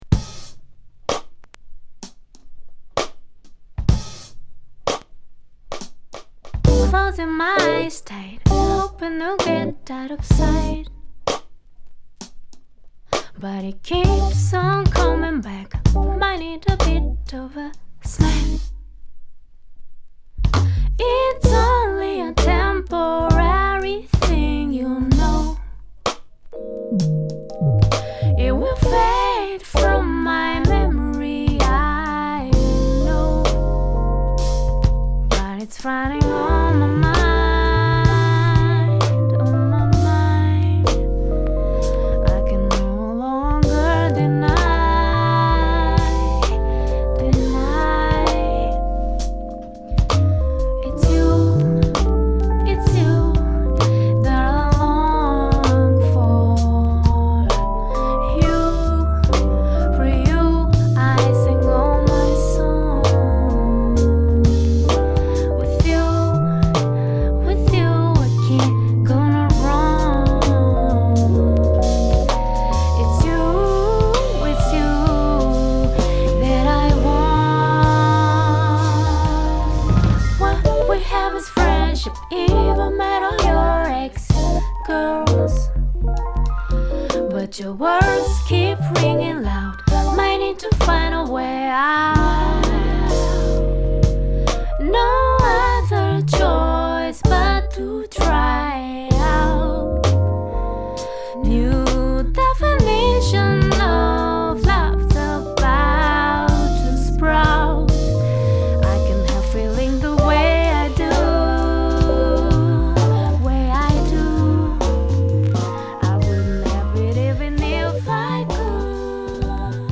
90'sヒップホップ〜R&Bをルーツにヒューマンビートボックスもこなす実力派女性シンガーソングライター